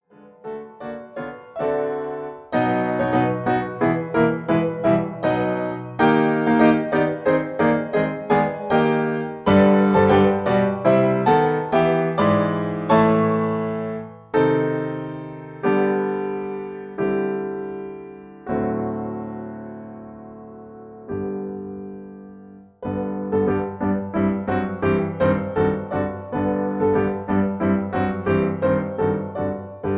In G major.